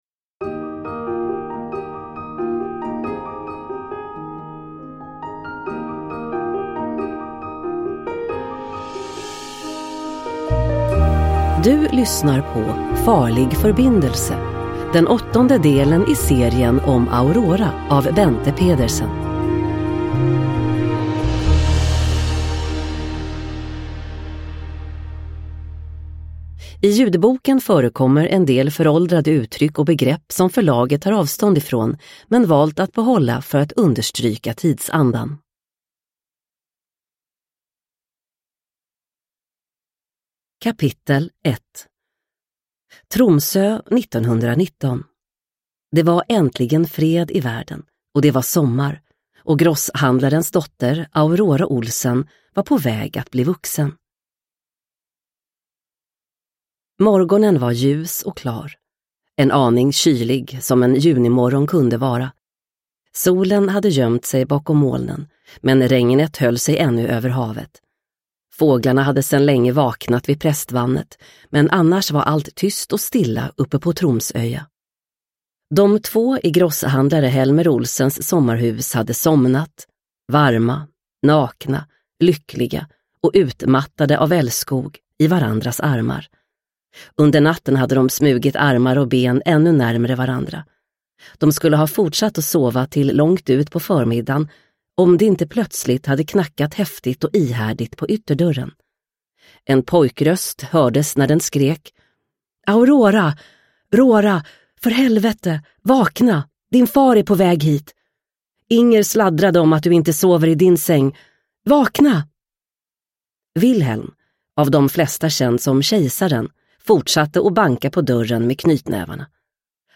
Farlig förbindelse – Ljudbok – Laddas ner